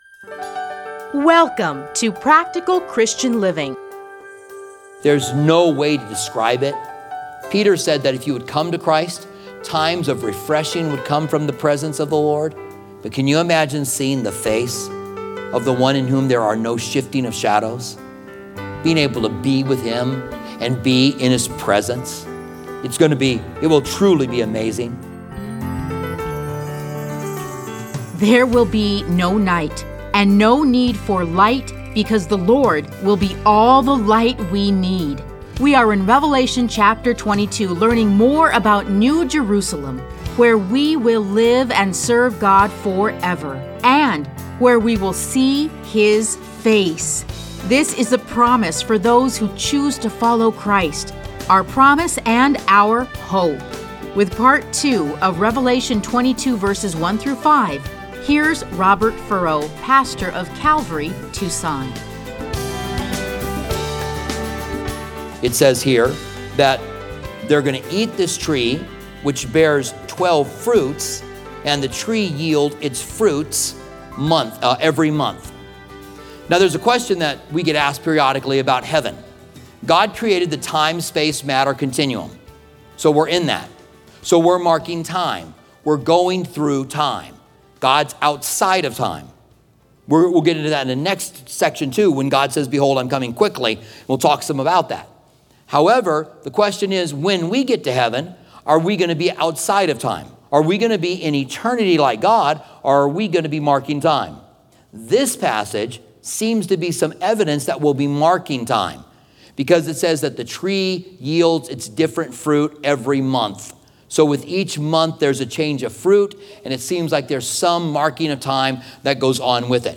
Listen to a teaching from Revelation 22:1-5.